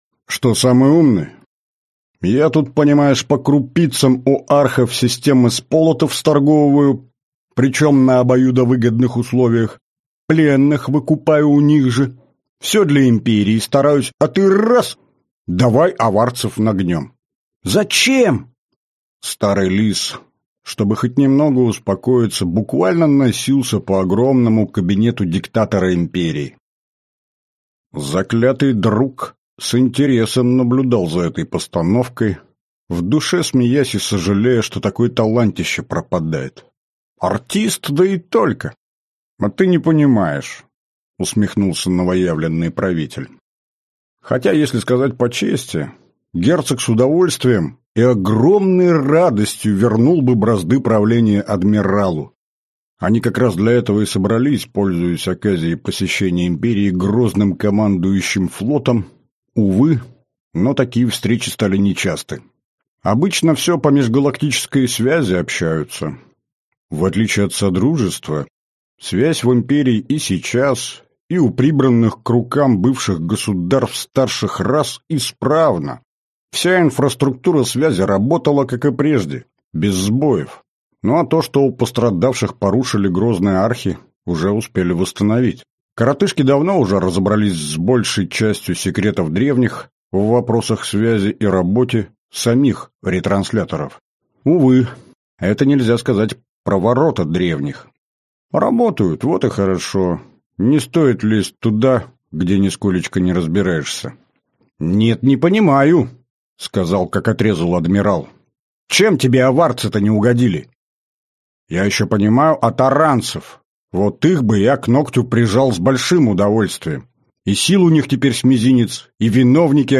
Аудиокнига Далекие миры. Император по случаю. Книга пятая. Часть третья | Библиотека аудиокниг